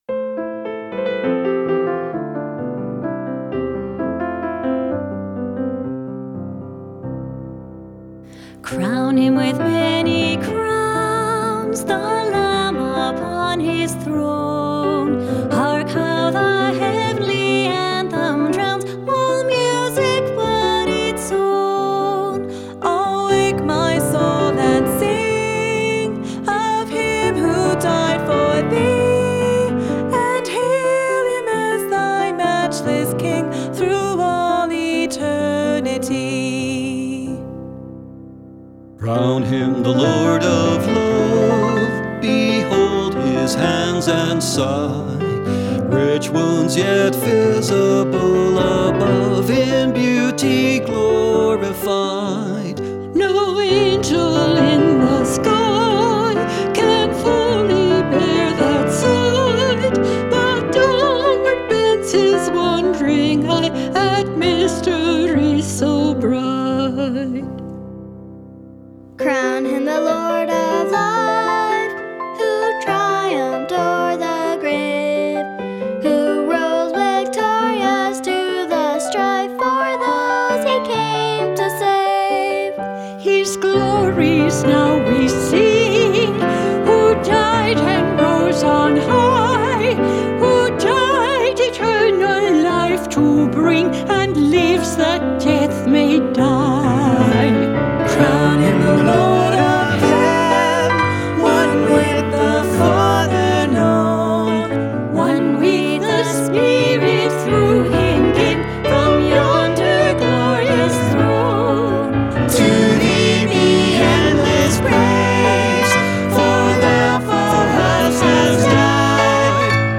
Traditional Hymn
Vocals
Piano